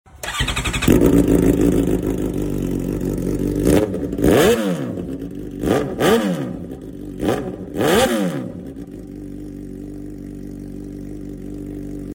Racefit full exhaust system SOUND